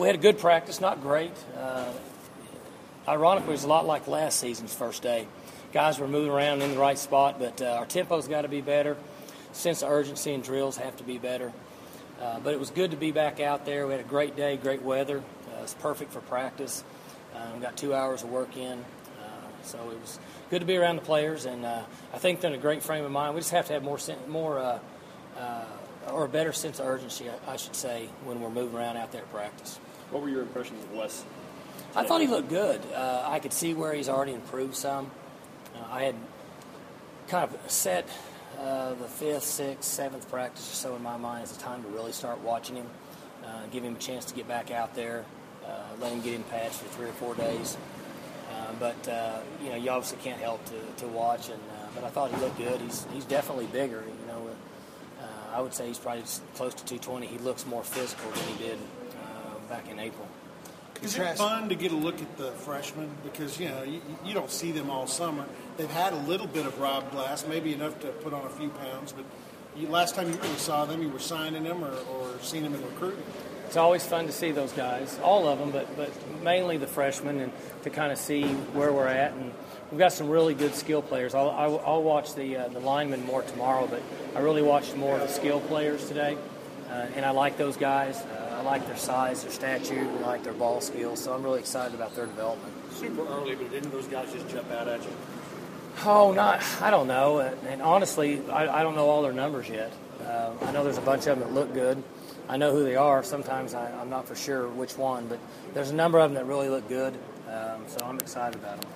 Oklahoma State head coach Mike Gundy gives his thoughts on the Cowboys' August 3, 2012 practice.